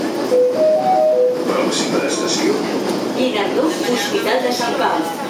Metro-ProximaStationeHospitalSanPau.mp3